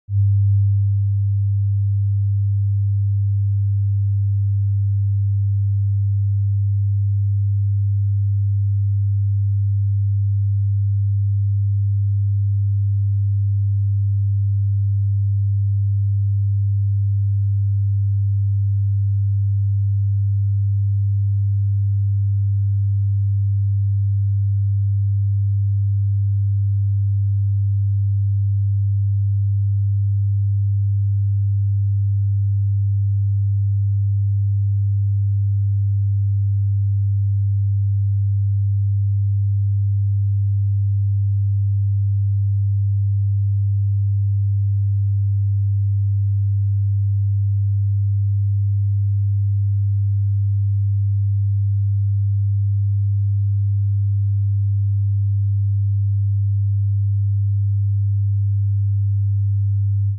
Eine Minute 100 Hertz: Der Ton gegen Reisekrankheit
Genau 100 Hertz muss der haben, sagen die Forschenden der japanischen Universität Nagoya.